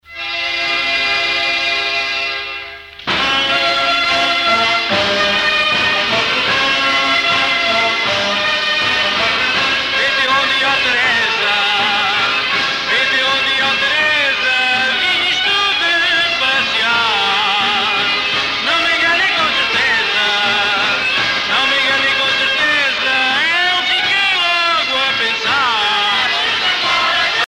danse : vira (Portugal)
Pièce musicale éditée